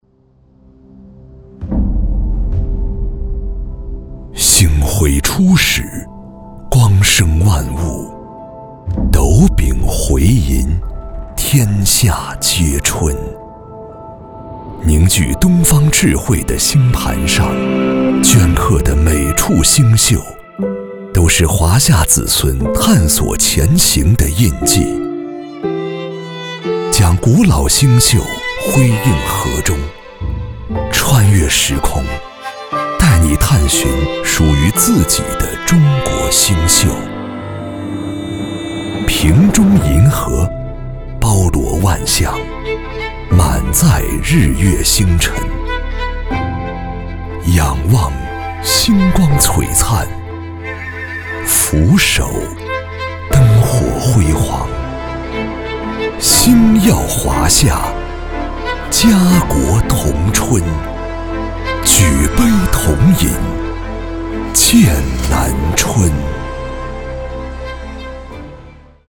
男4号（厚重、大气）
男4-酒广告-剑南春DEMO
男4-酒广告-剑南春DEMO.mp3.mp3